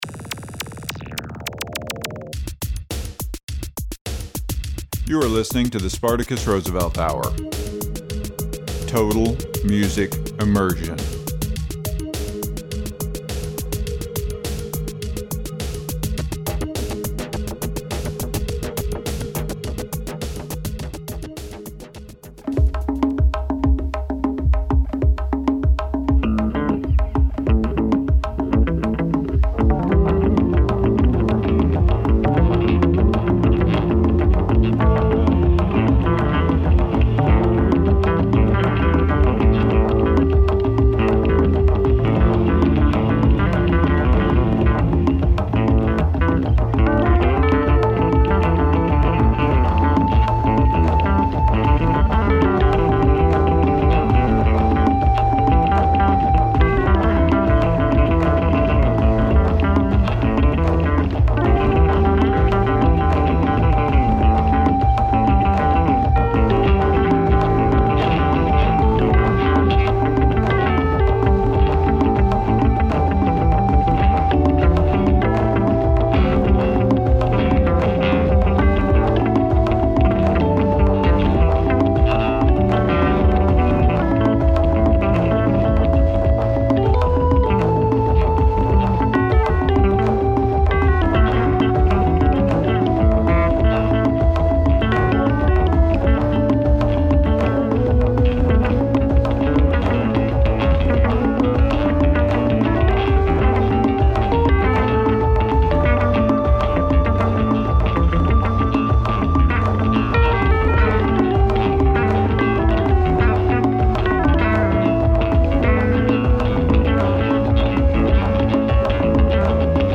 Total Music Immersion
Alternately jazzy with some vocals, this epidode hits.